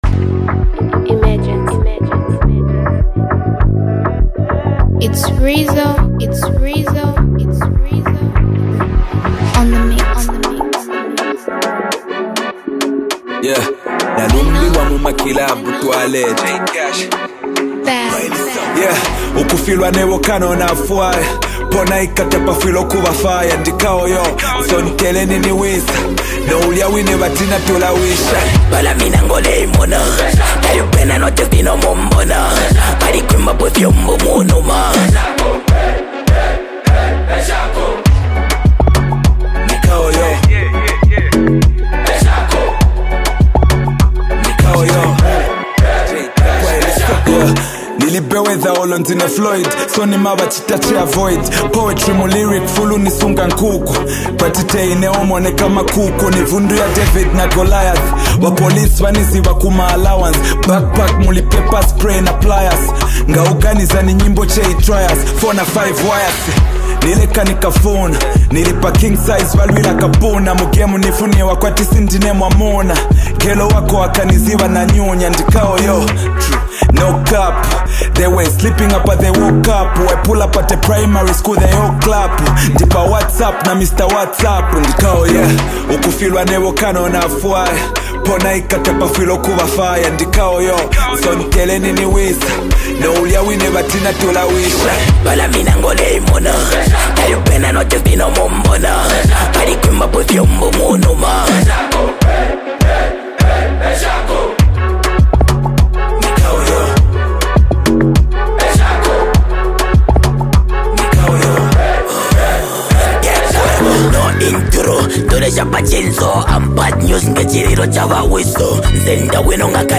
energetic production